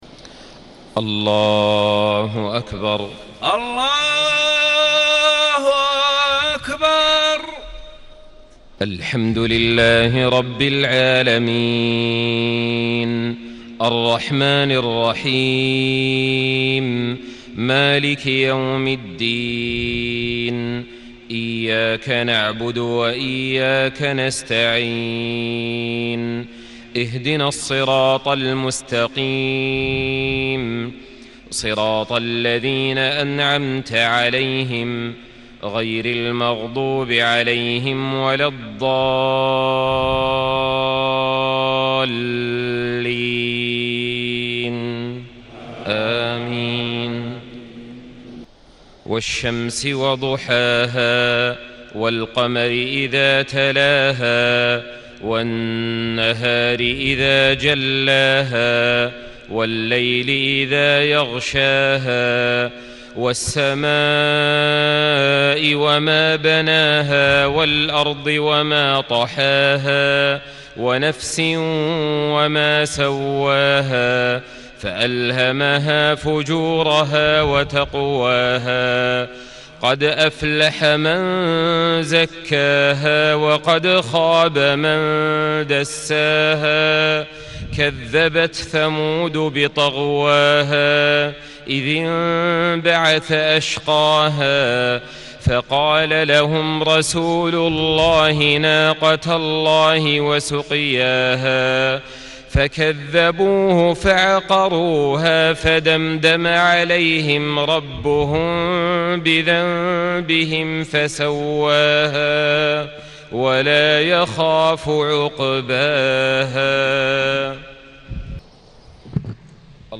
صلاة العشاء 1رمضان 1437هـ سورتي الشمس و التين > 1437 🕋 > الفروض - تلاوات الحرمين